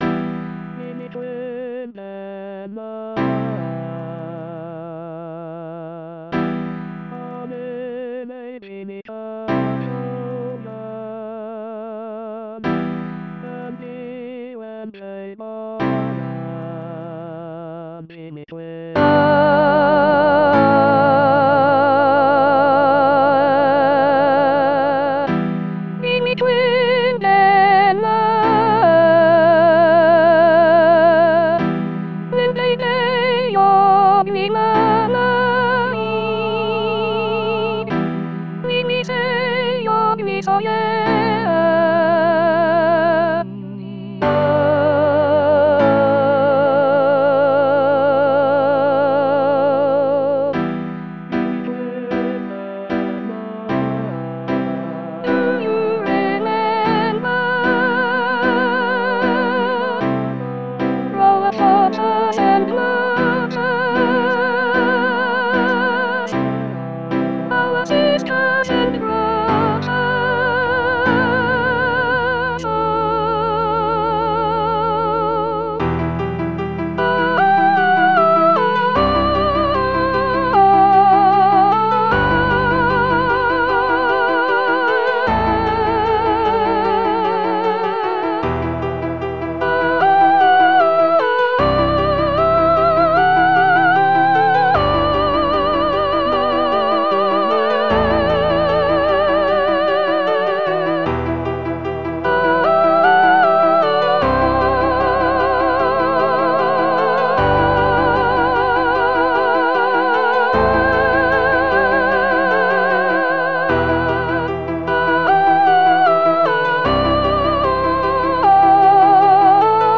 Soprano Soprano 2